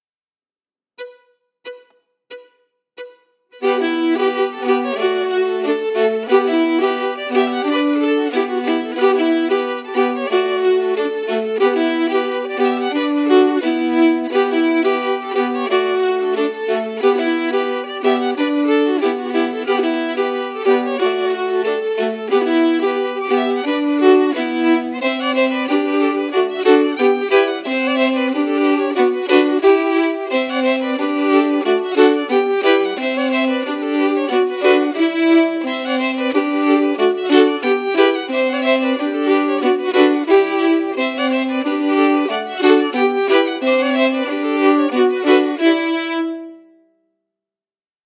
Key: E Dorian
Form: Reel
Harmony emphasis
Source: Trad.